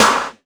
SHOT ROOM.wav